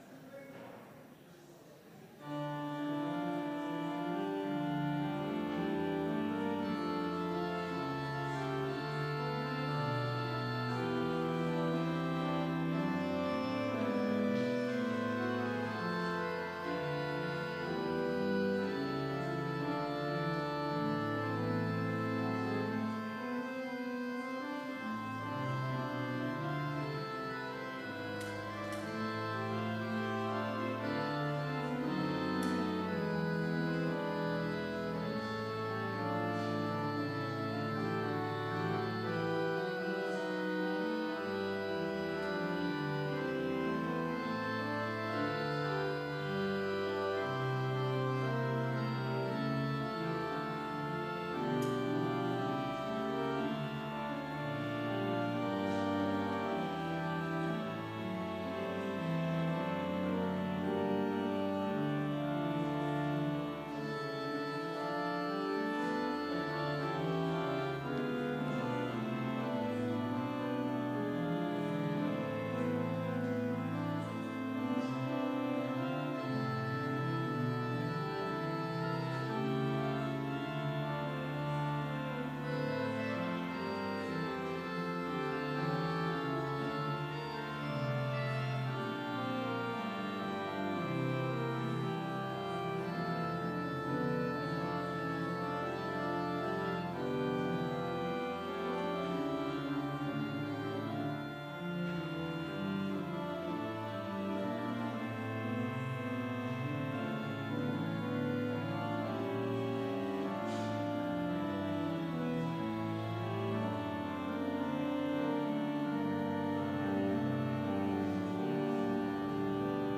Complete service audio for Vespers - October 9, 2019